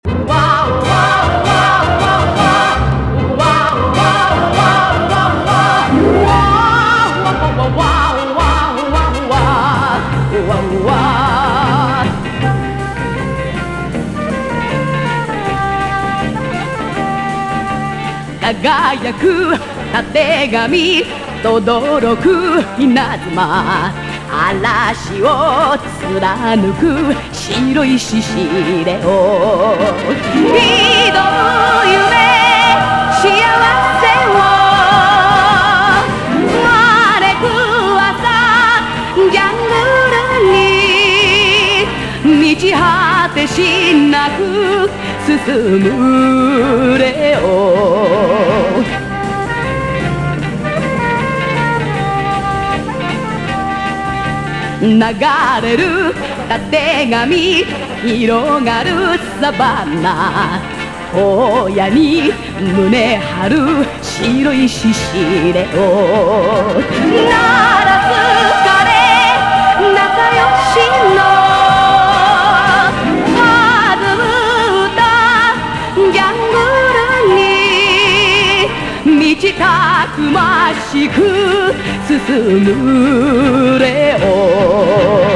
EASY LISTENING / EASY LISTENING / TV THEME / LIBRARY